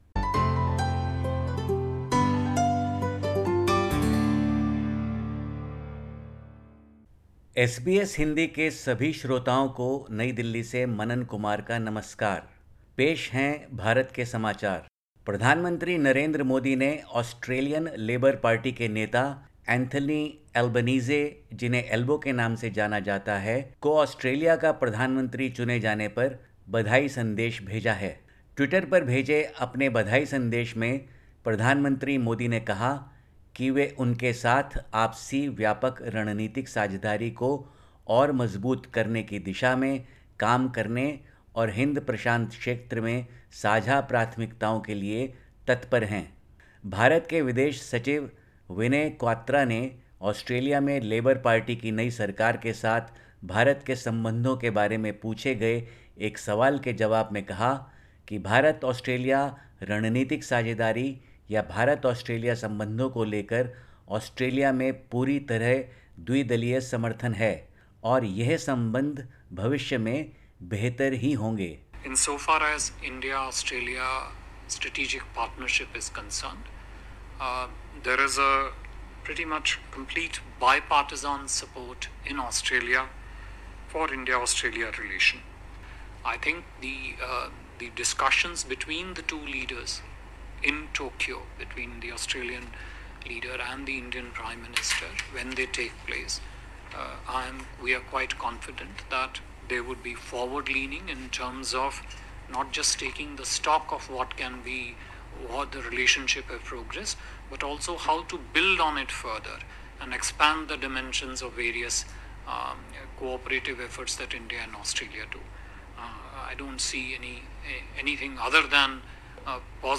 Listen to the latest SBS Hindi report from India. 23/05/2022